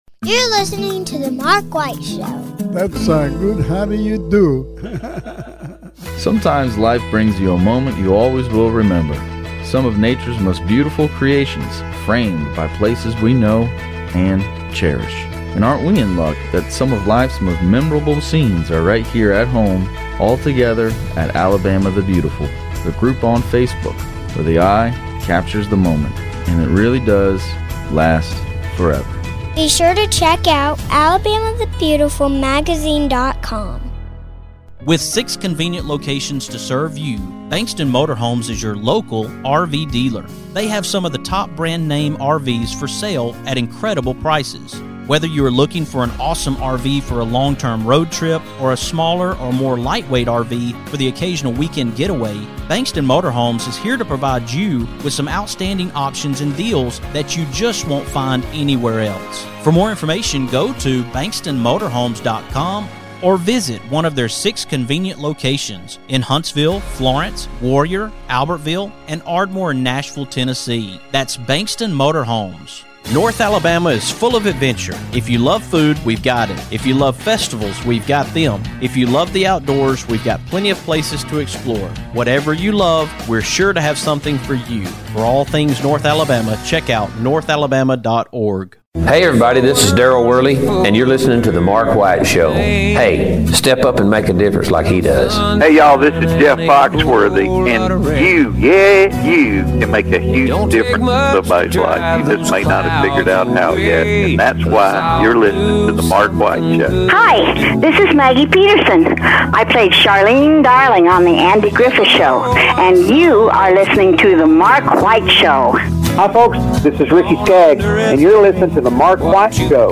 On tonight's show, I am coming to you from Barber Motorsports Museum as we are Racing for Children's in support of Children's of Alabama!